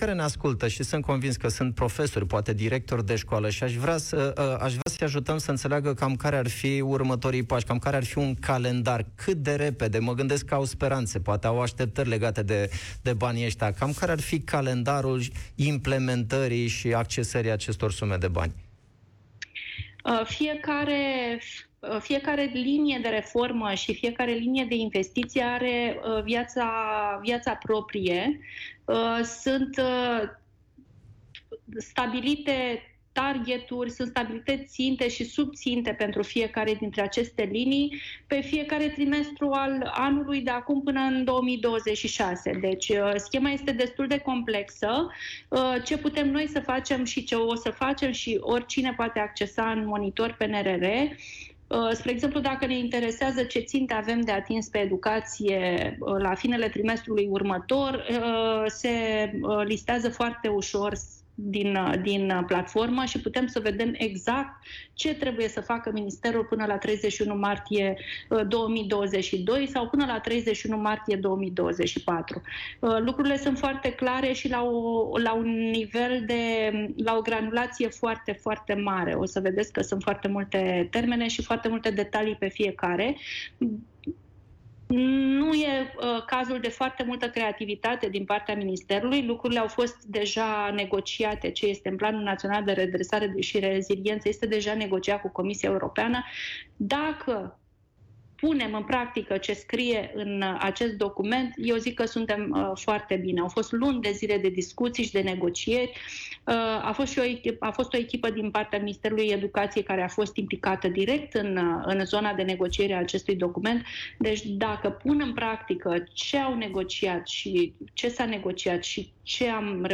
Corina Atanasiu, fost secretar de stat în Ministerul Investițiilor și Proiectelor Europene și reprezentanta asociației de părinți FEPAL a vorbit în emisiunea Piața Victoriei, la Europa FM, despre Monitor PNRR, platforma care oferă o imagine clară asupra Planului Național de Redresare și Reziliență și a modului în care se implementează.